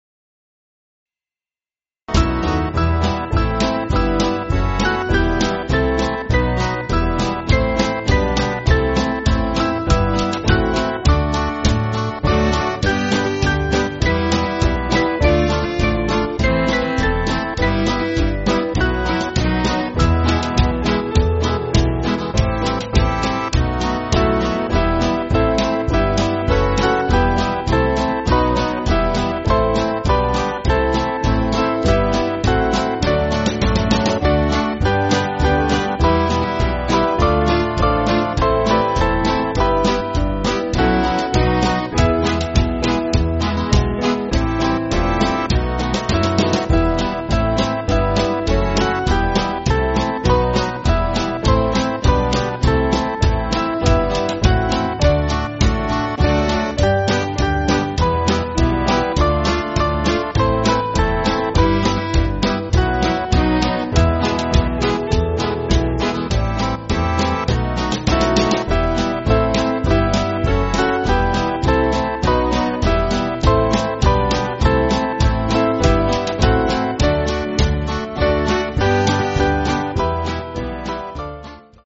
Small Band
(CM)   7/Em